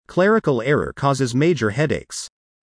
以下、設問１）〜４）の不正解答案文を音読したネイティブ音声を出題しました。
正解の読み上げ音声